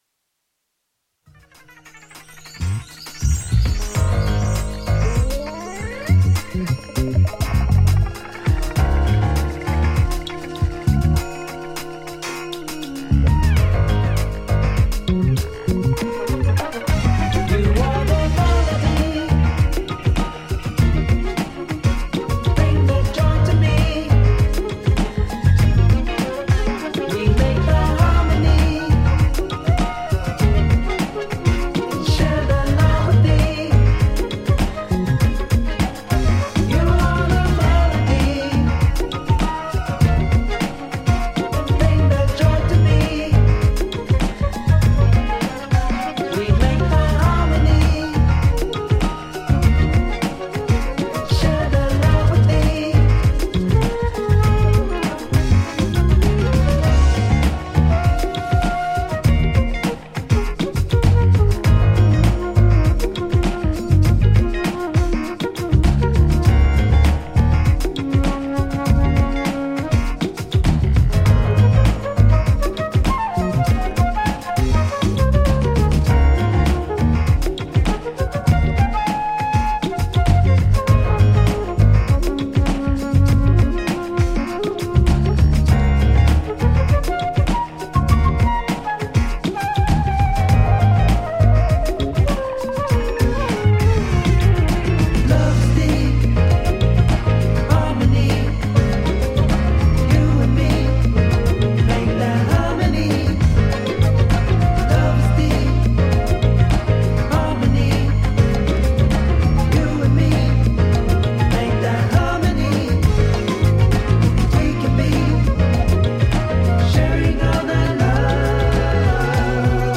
ジャンル(スタイル) NU DISCO / DEEP HOUSE / BOOGIE